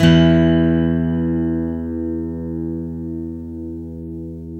Index of /90_sSampleCDs/Roland - Rhythm Section/GTR_Steel String/GTR_12 String
GTR 12STR 00.wav